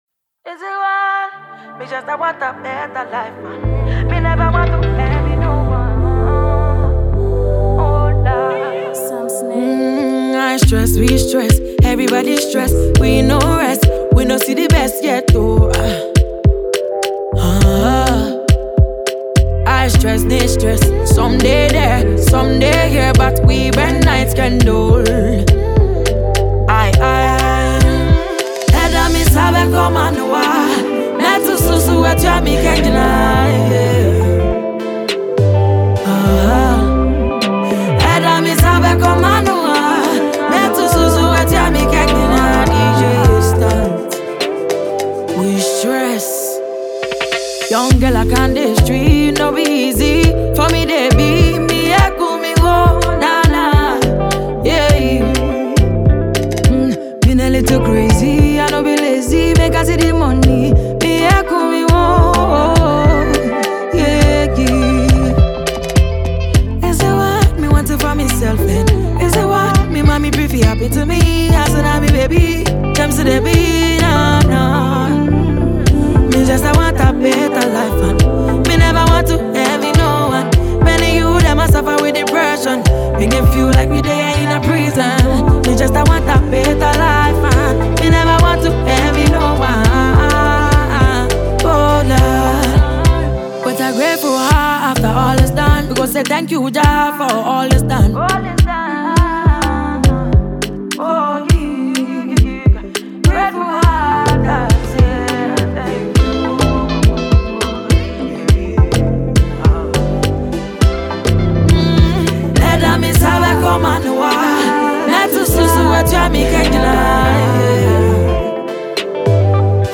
a Ghanaian female artist